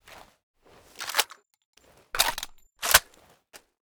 4aef571f59 Divergent / mods / Tommy Gun Drop / gamedata / sounds / weapons / thompson / m1a1_new_unjam.ogg 107 KiB (Stored with Git LFS) Raw History
m1a1_new_unjam.ogg